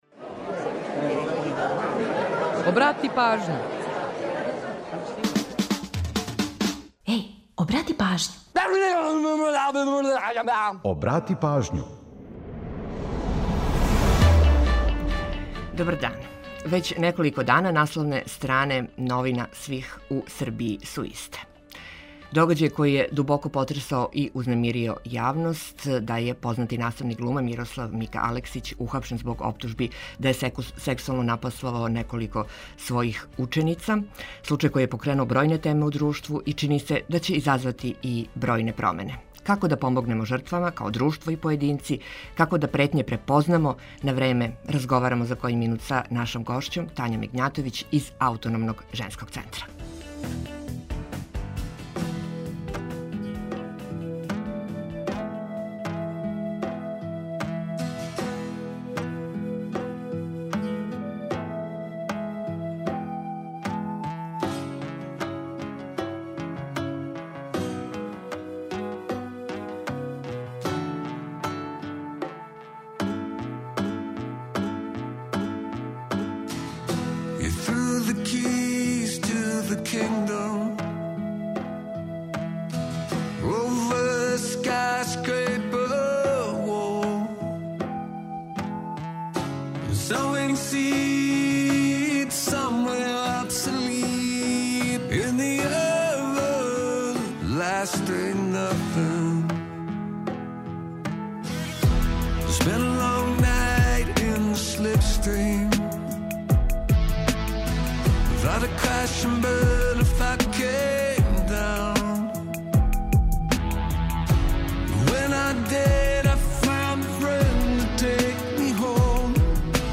У наставку емисије подсетићемо вас на важне догађаје у поп рок историји који су догодили на данашњи дан. Ту је и пола сата резервисаних за домаћицу, музику из Србије и региона, прича о једној песми и низ актуелних занимљивости и важних информација.